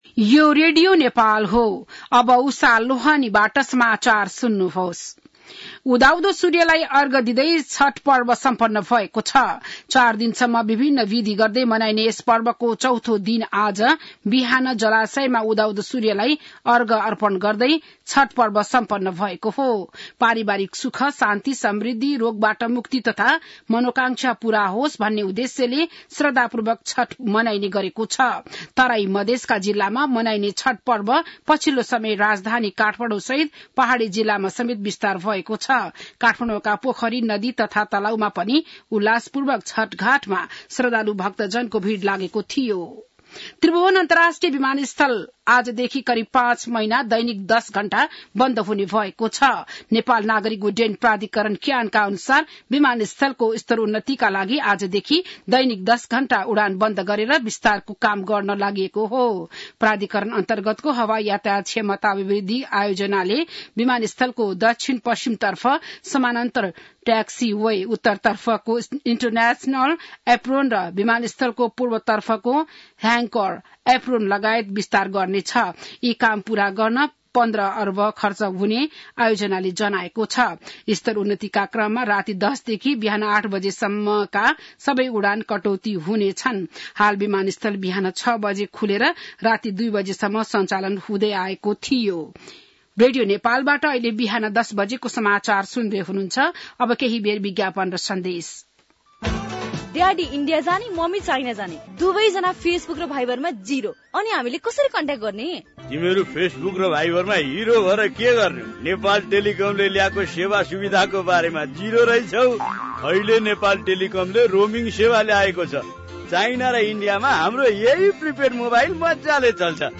बिहान १० बजेको नेपाली समाचार : २४ कार्तिक , २०८१